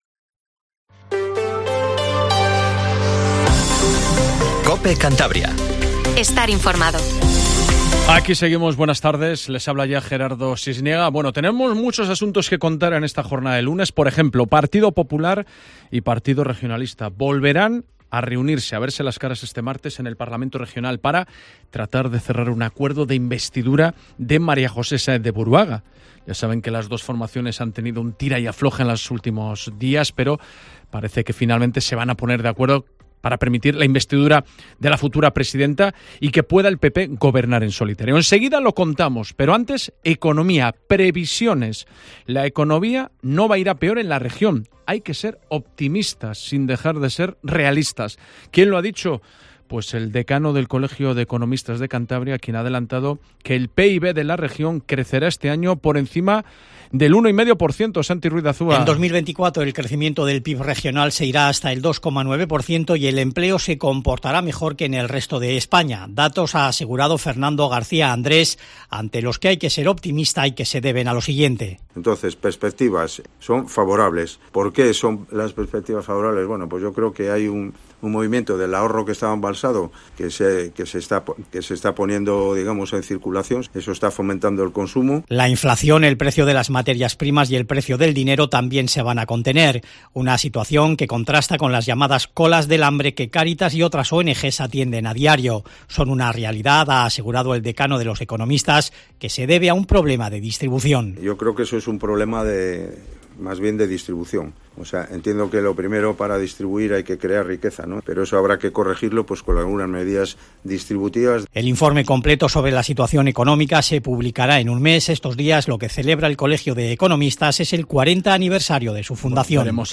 Informativo Mediodía COPE CANTABRIA